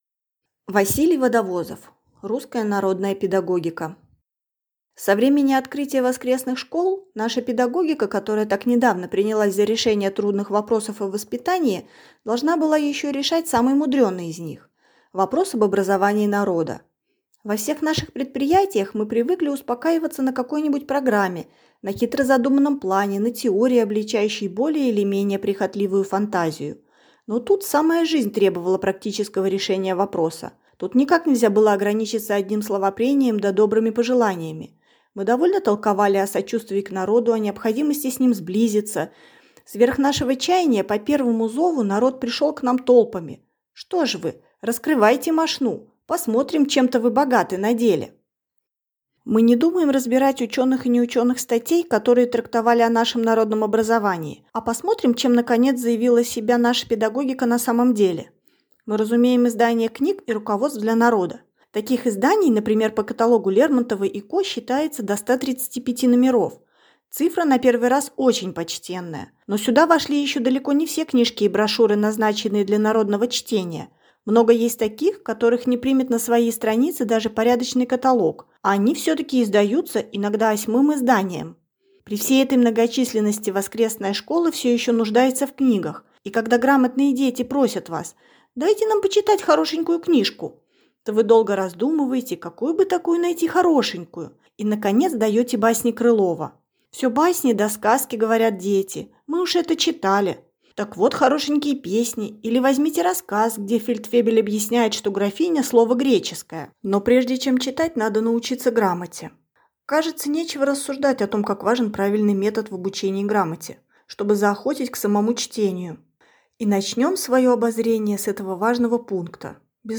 Аудиокнига Русская народная педагогика | Библиотека аудиокниг